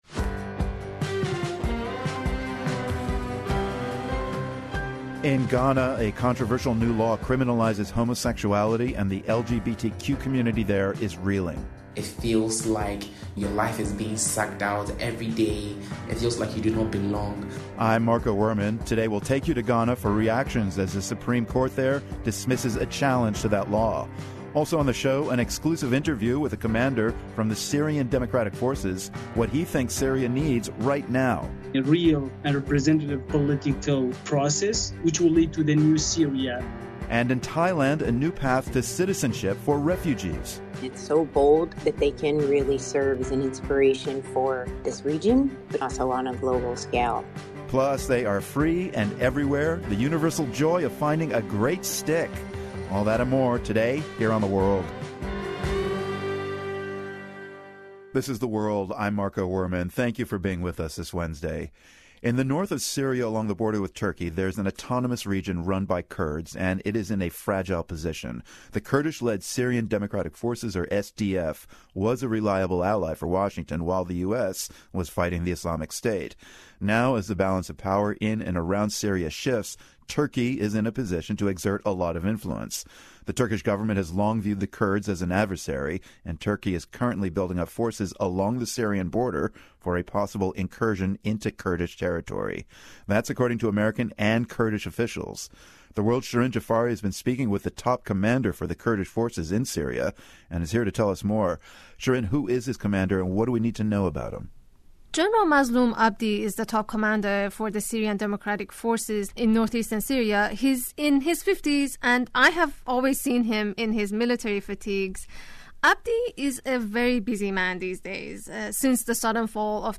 As Syria goes through historic changes, minority groups remain on edge. The commander of the US-backed Kurdish SDF discusses the situation in an exclusive interview with The World. Also, the tiny island nation of Vanuatu struggles to recover from a powerful earthquake. And, a controversial anti-LGBTQ bill in Ghana is one step closer to becoming law.